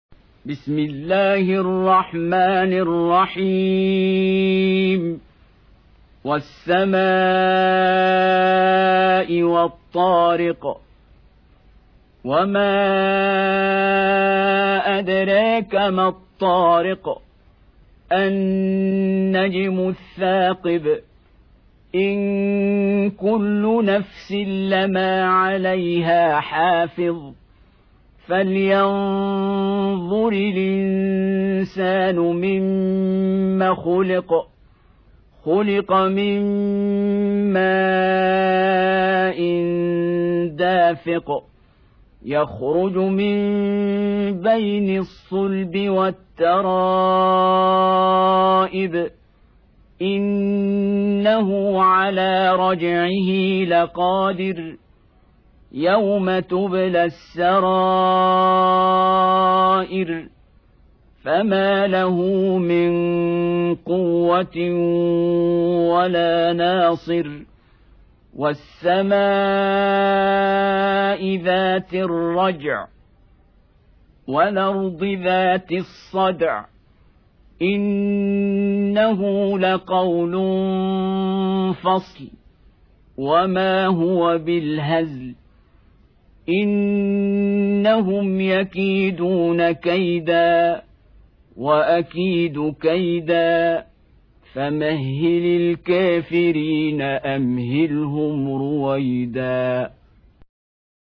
Audio Quran Tarteel Recitation
Surah Repeating تكرار السورة Download Surah حمّل السورة Reciting Murattalah Audio for 86. Surah At-T�riq سورة الطارق N.B *Surah Includes Al-Basmalah Reciters Sequents تتابع التلاوات Reciters Repeats تكرار التلاوات